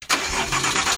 crank.wav